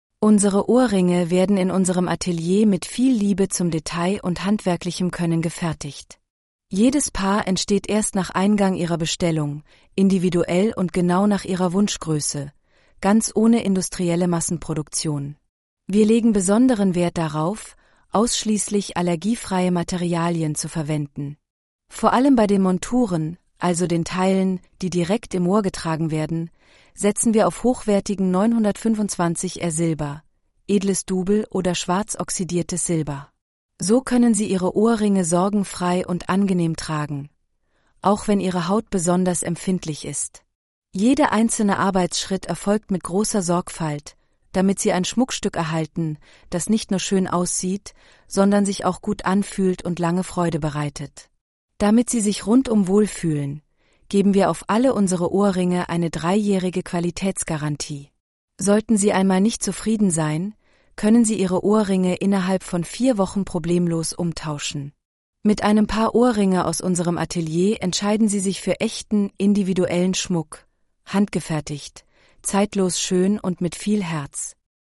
Ohrring-ttsreader.mp3